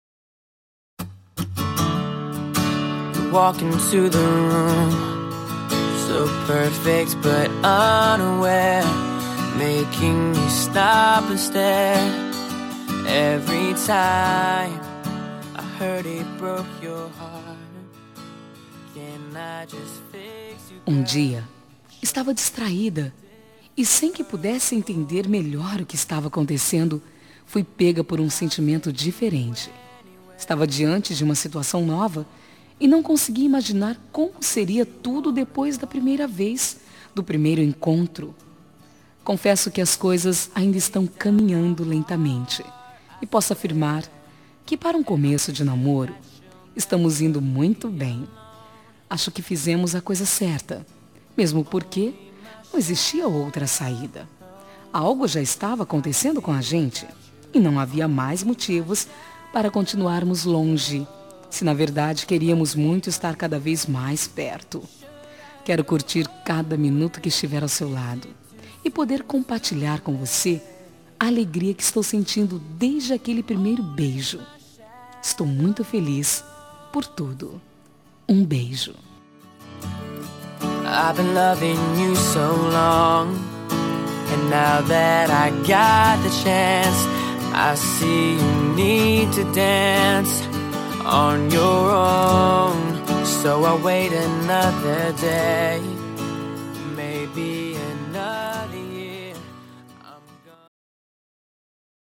Telemensagem Início de Namoro – Voz Feminina – Cód: 744